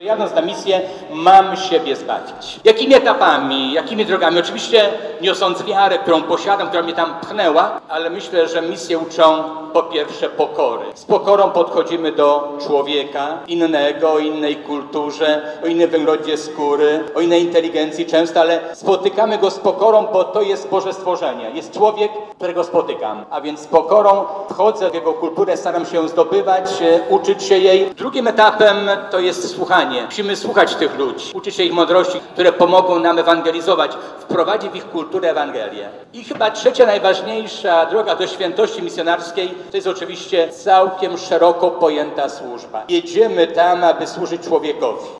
W kościele, wypełnionym po brzegi